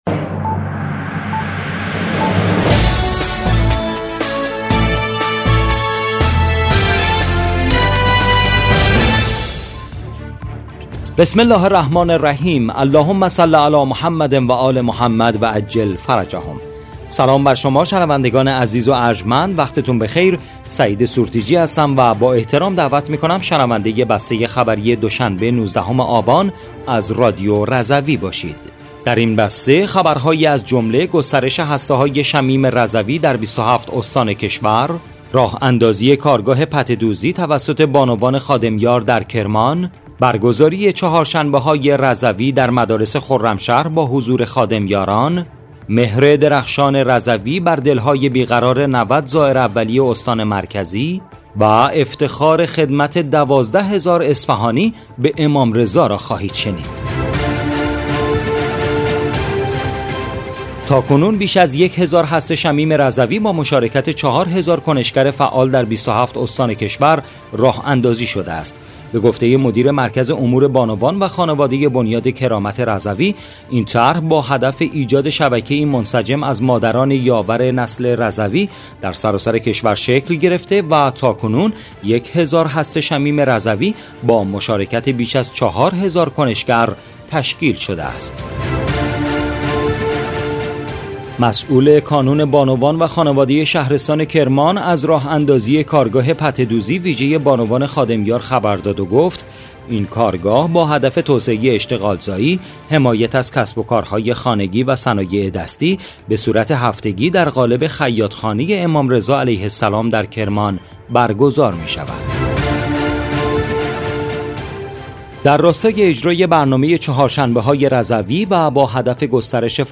بسته خبری ۱۹ آبان ۱۴۰۴ رادیو رضوی؛